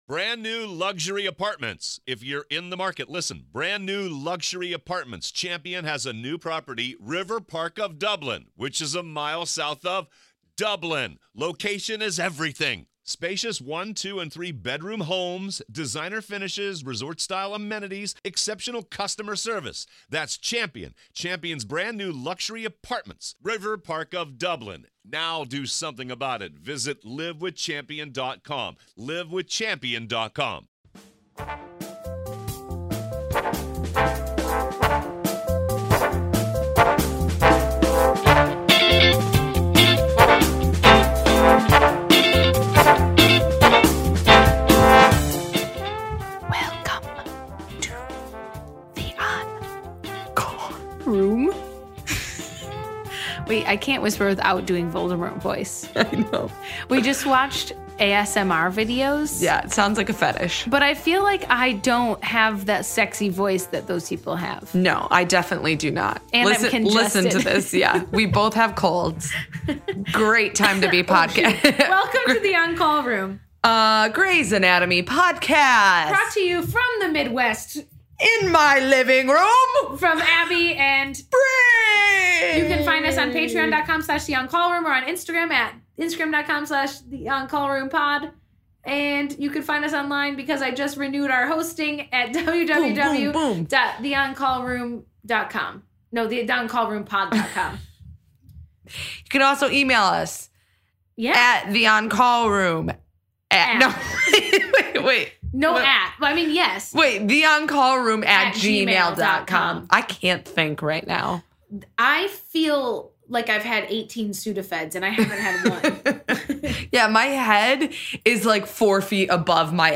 have a head colds and probably fevers as they record an intro that you will either love or hate